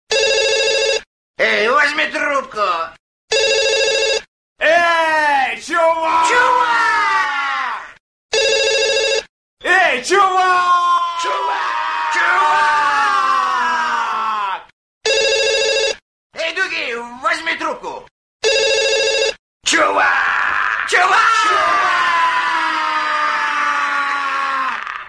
Prank - Dude scream.
• Category: Men's scream